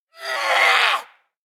DayZ-Epoch/SQF/dayz_sfx/zombie/chase_12.ogg at 02c910078a3cafd47863f5c1aeaccbed9020f768
chase_12.ogg